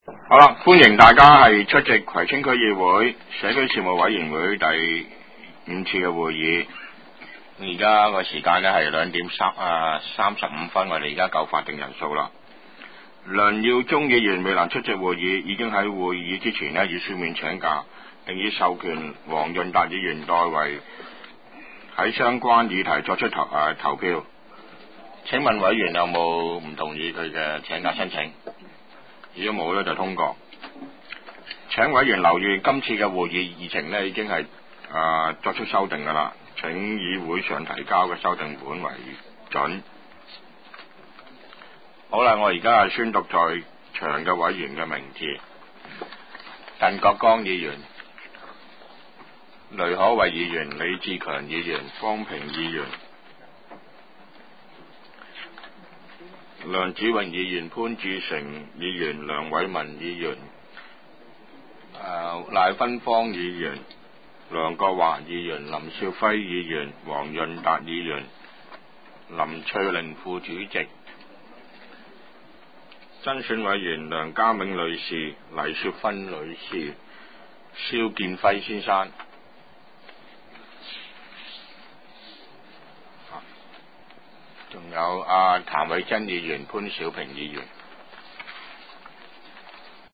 第五次會議(一零/一一)
葵青民政事務處會議室